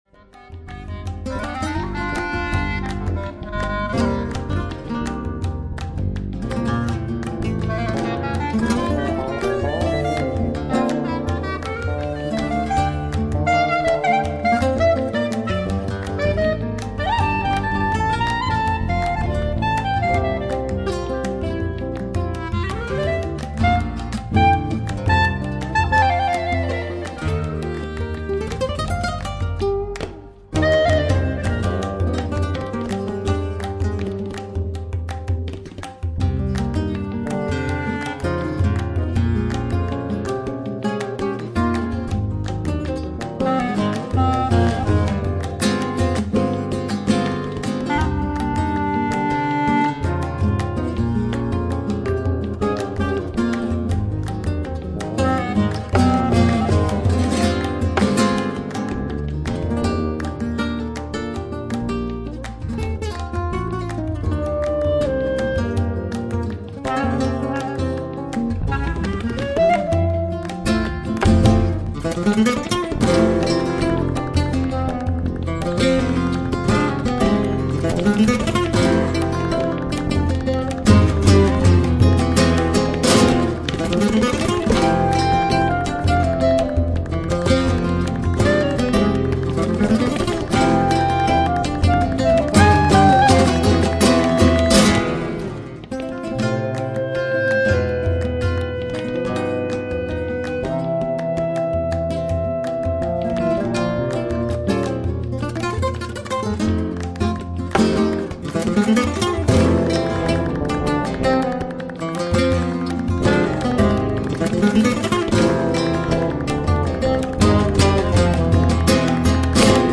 • Lakland 5 String Daryl Jones Hybrid played by